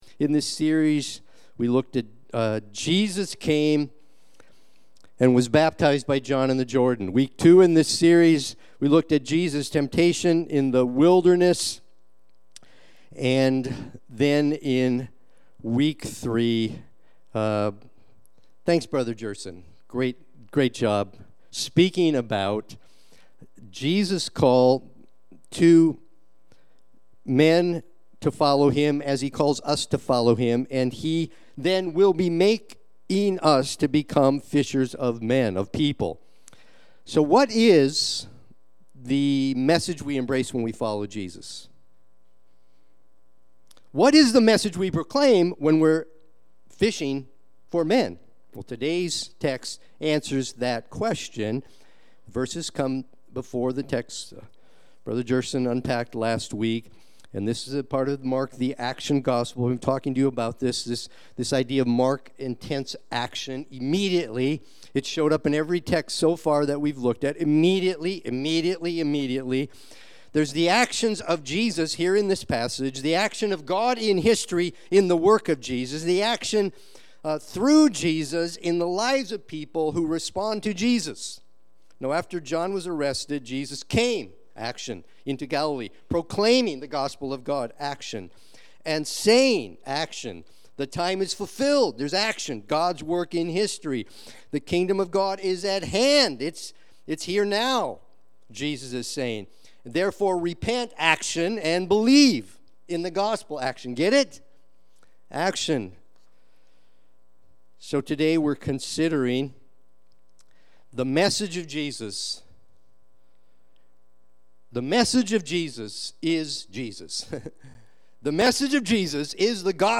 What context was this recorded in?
Sunday-Worship-main-10525.mp3